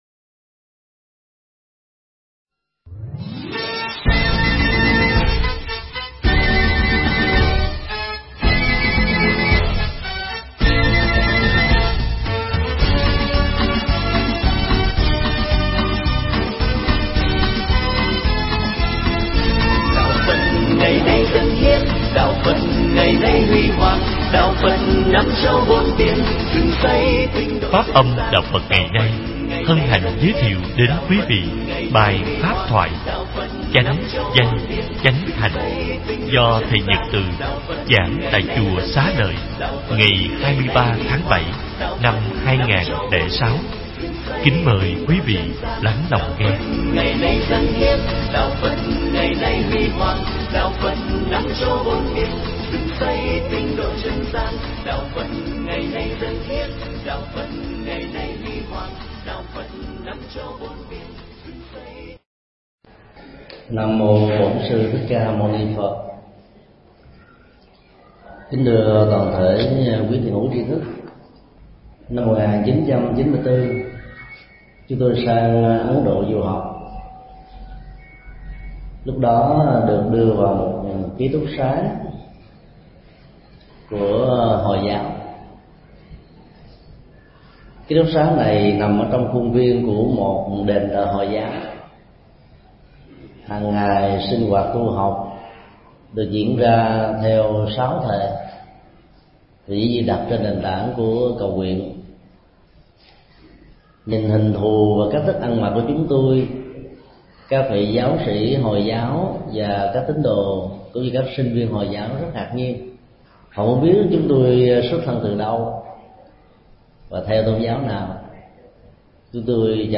Tải mp3 Pháp thoại Kinh Trung Bộ 40
Giảng tại chùa Xá Lợi